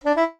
jingles-saxophone_07.ogg